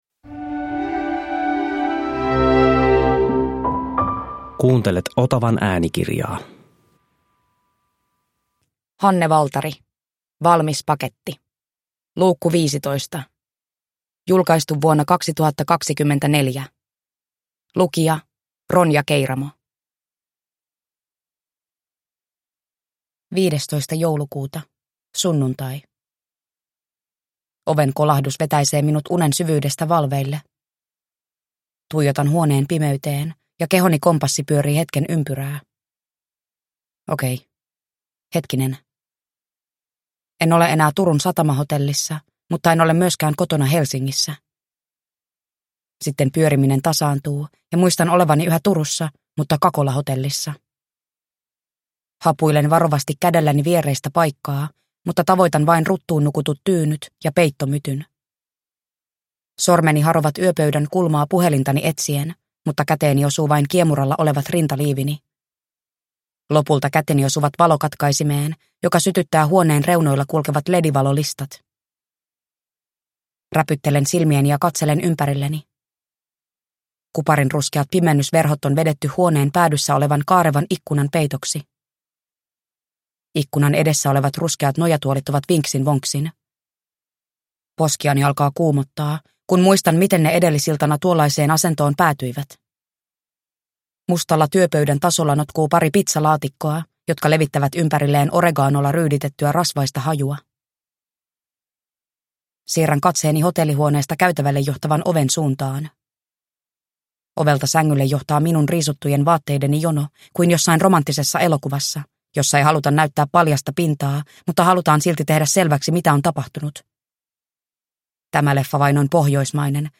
Valmis paketti 15 (ljudbok) av Hanne Valtari